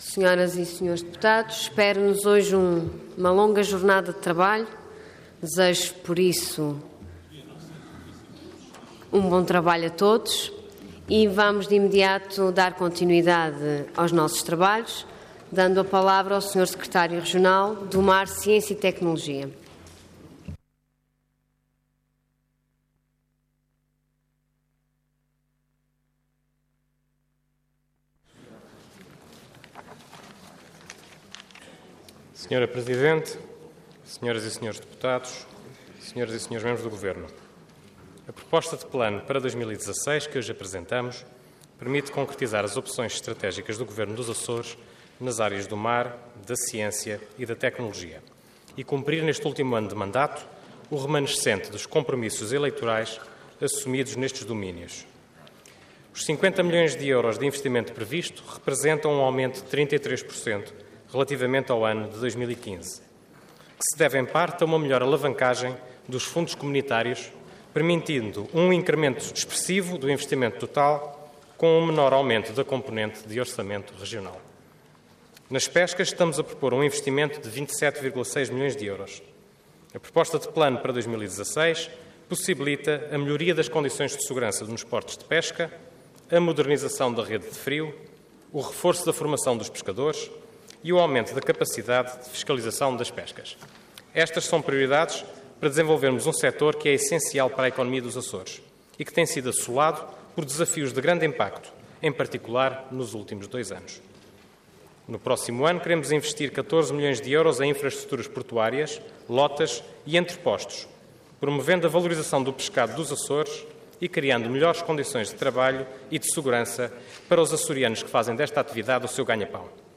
Detalhe de vídeo 26 de novembro de 2015 Download áudio Download vídeo X Legislatura Plano e Orçamento para 2016 - Mar, Ciência e Tecnologia Intervenção Proposta de Decreto Leg. Orador Fausto Abreu Cargo Secretário Regional do Mar, Ciência e Tecnologia Entidade Governo